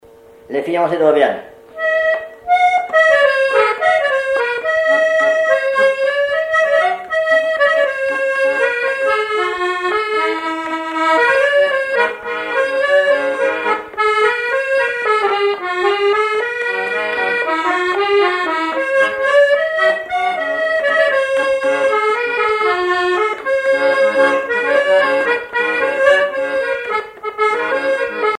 accordéon(s), accordéoniste
danse : valse musette
Pièce musicale inédite